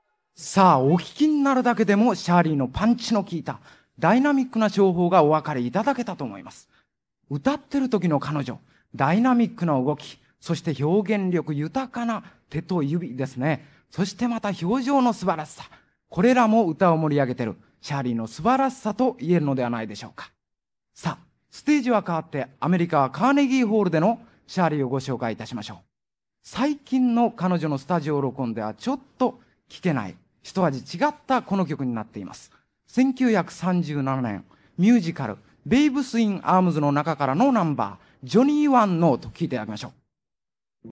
1976年2月、高田馬場BIGBOXで開催されたDJ大会。
▶ DJ音声③（2曲目紹介）
③DJの声-2曲目-ジョニーワンの紹介まで-3.mp3